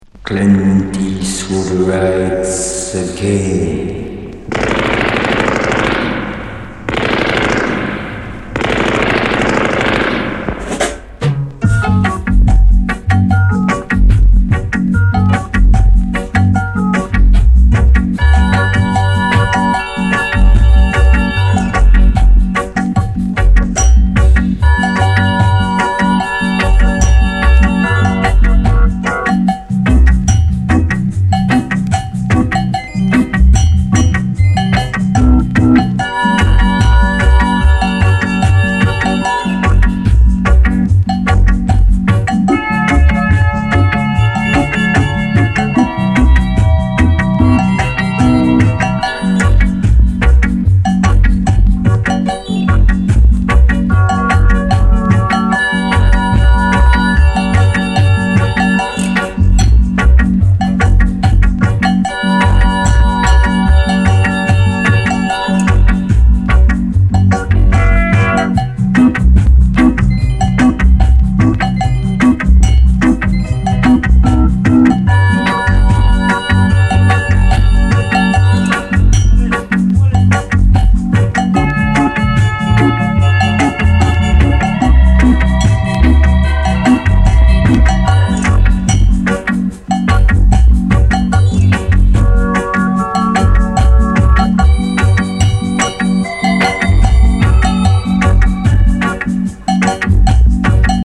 銃声やハモンド効かせたスキンズ・チューンなどルーディー・チューン満載！